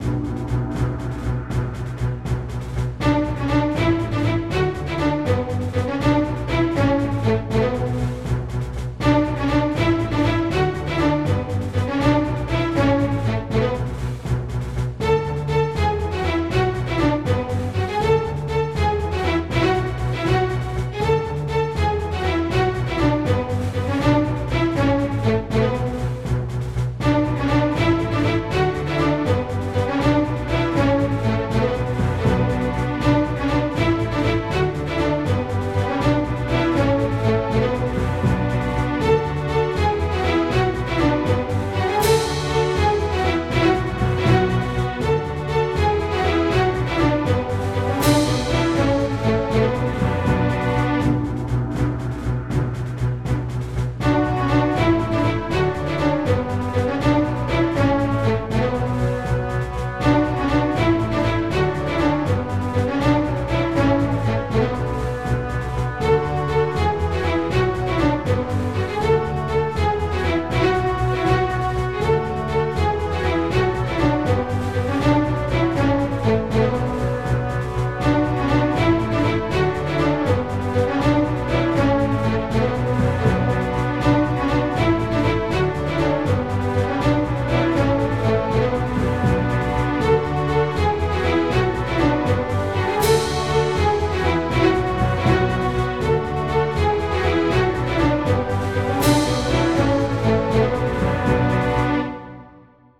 It's loopable now, at least.
If it's the battle music... that is already using a very expensive set of sounds.
main-battle-theme.mp3